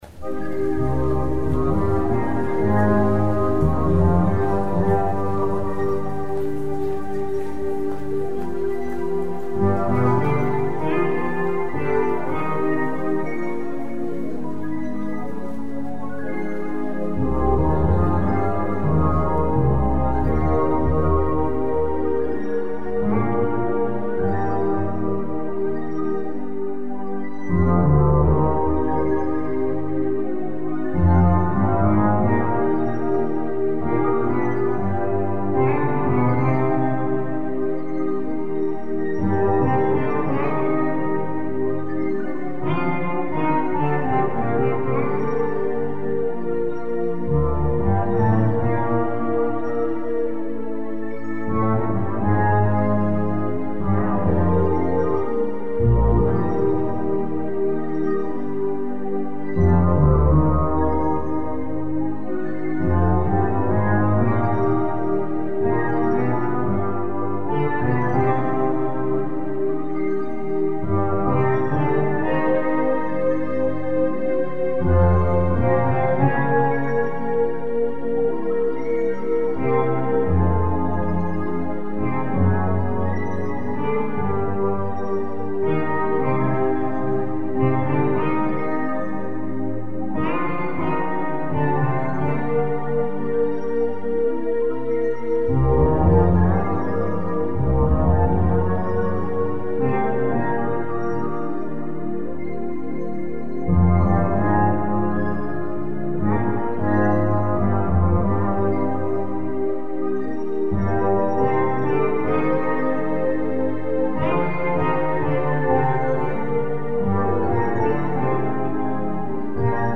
FULL VERSION /128 kbit/s stereo /